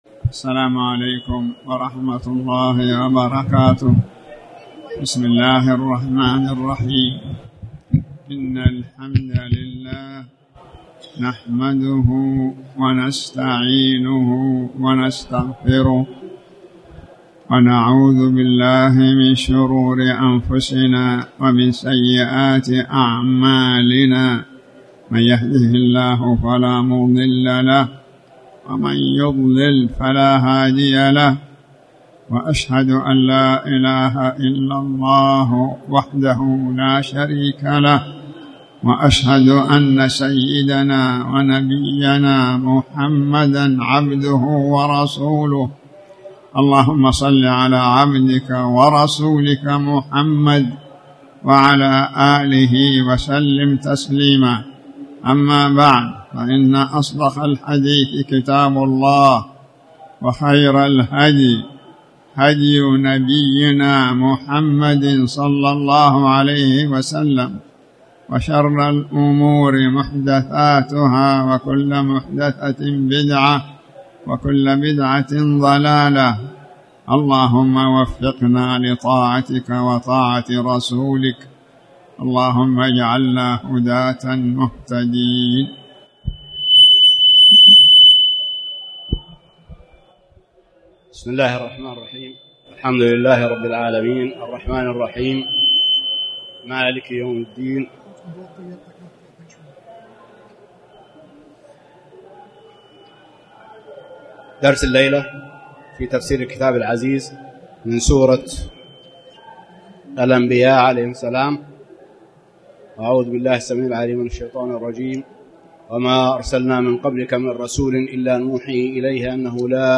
تاريخ النشر ٧ محرم ١٤٣٩ هـ المكان: المسجد الحرام الشيخ